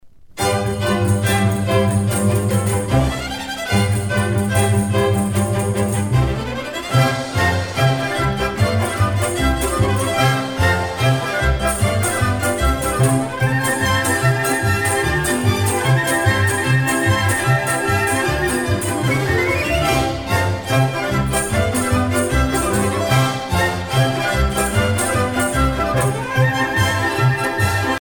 Usage d'après l'analyste gestuel : danse ;
Catégorie Pièce musicale éditée